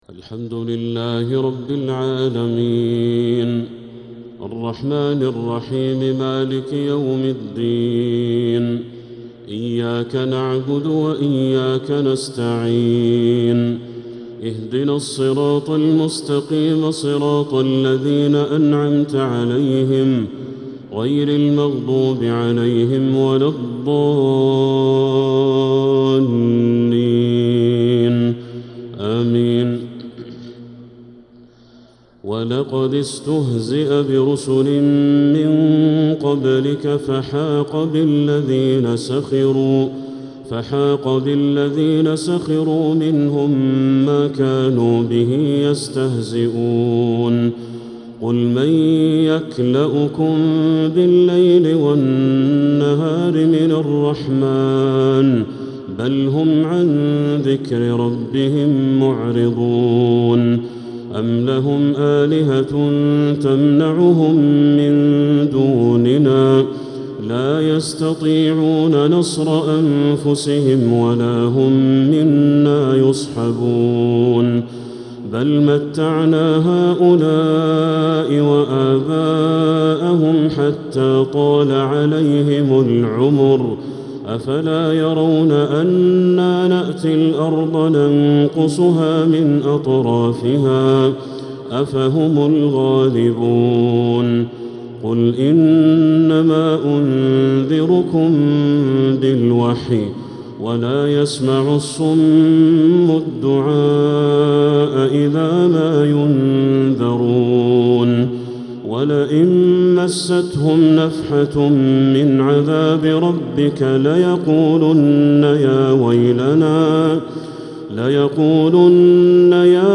تهجد ليلة 29 رمضان 1446هـ من سورة الأنبياء 41-112 | Tahajjud 29th night Ramadan 1446H Surah Al-Anbiya > تراويح الحرم المكي عام 1446 🕋 > التراويح - تلاوات الحرمين